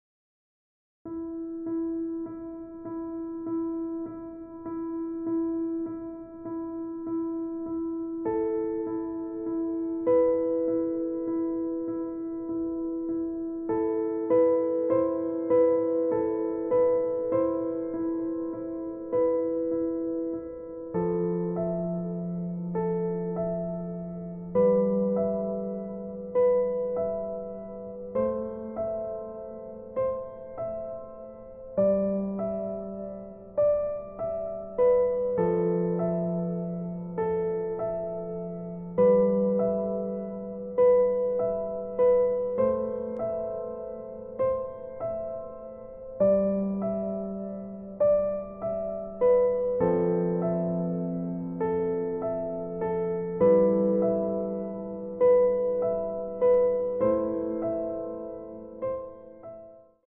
EASY Piano Tutorial